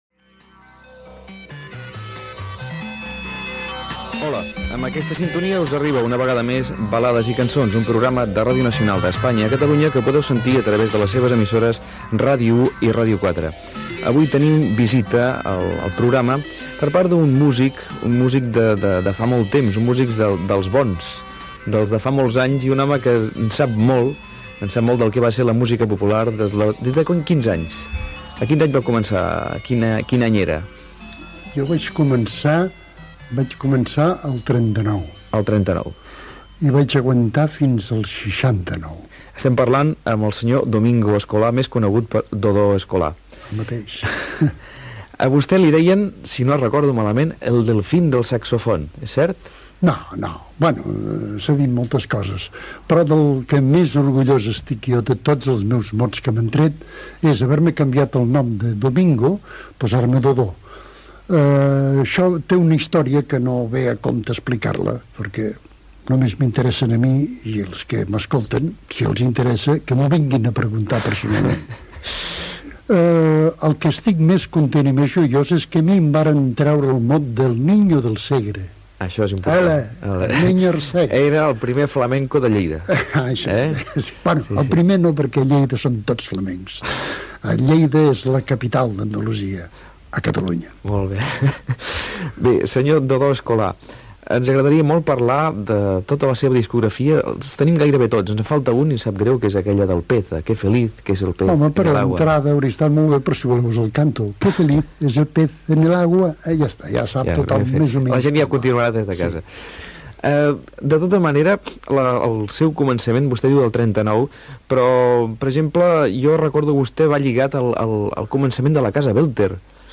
Musical